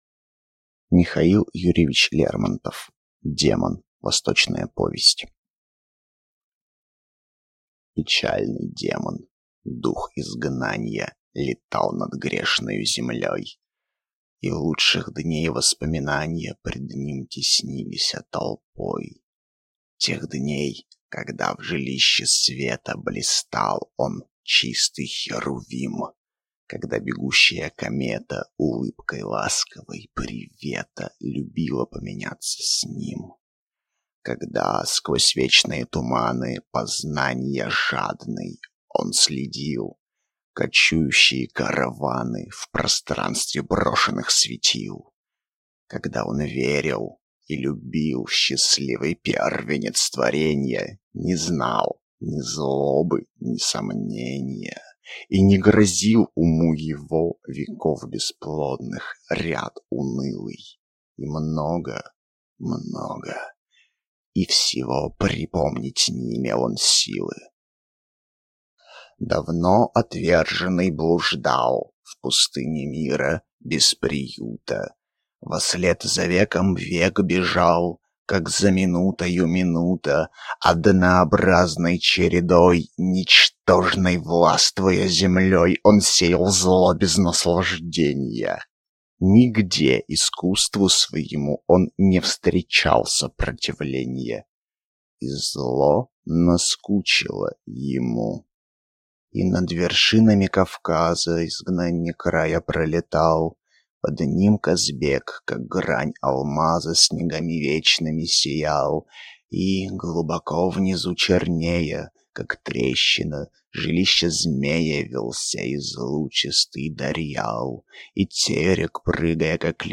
Аудиокнига Демон | Библиотека аудиокниг